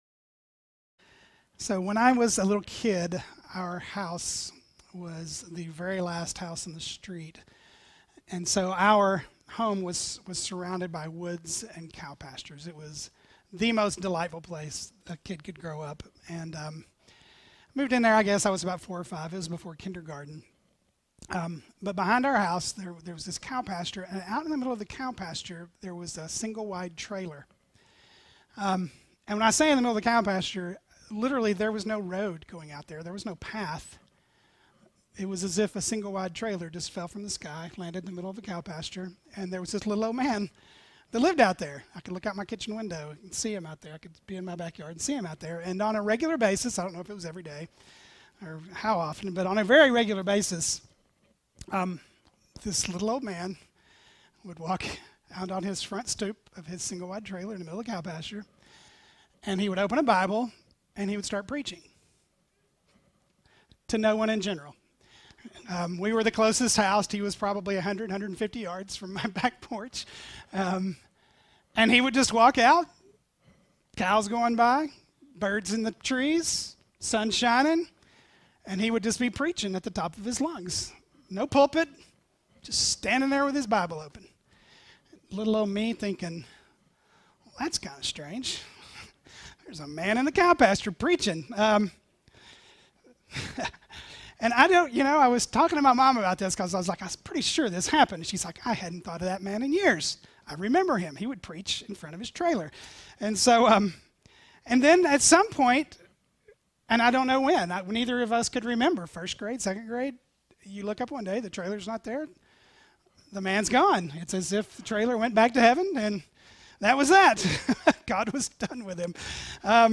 An Easter service reflection on 1 Corinthians 15.